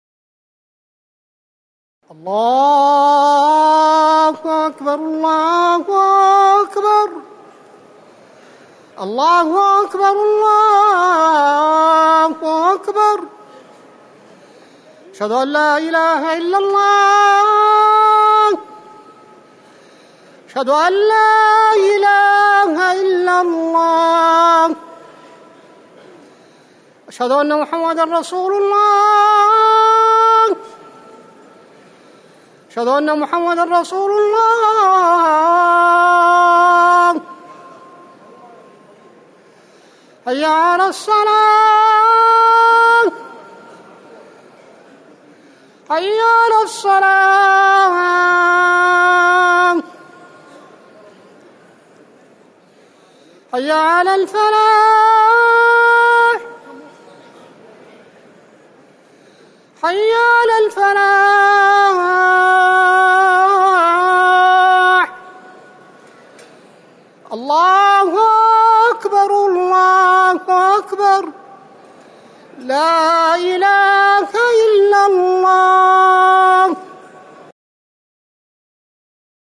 أذان المغرب - الموقع الرسمي لرئاسة الشؤون الدينية بالمسجد النبوي والمسجد الحرام
تاريخ النشر ١ محرم ١٤٤١ هـ المكان: المسجد النبوي الشيخ